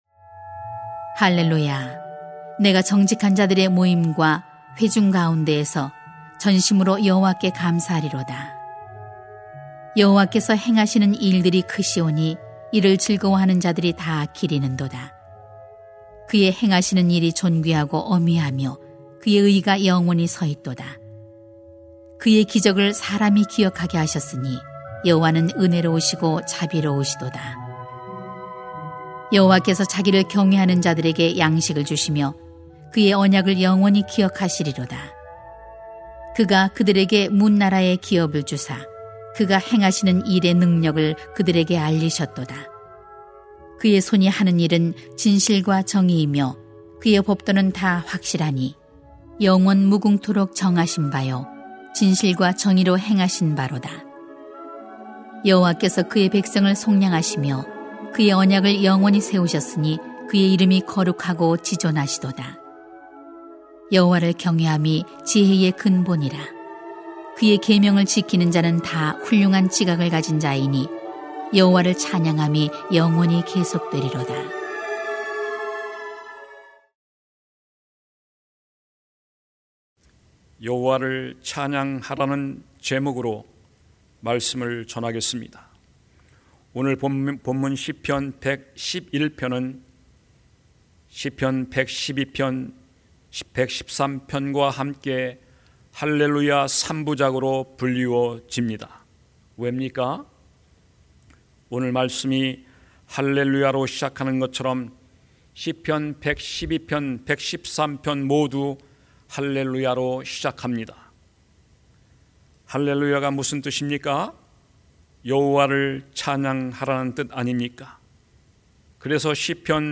[시 111:1-10] 여호와를 찬양하라 > 새벽기도회 | 전주제자교회